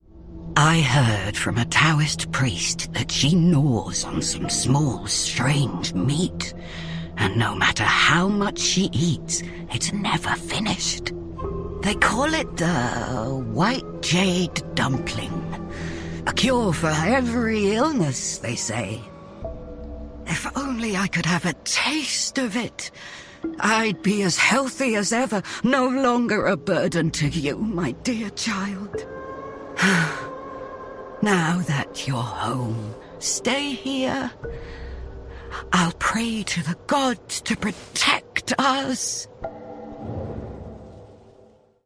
English dialogue performances directed by Pitstop Productions in London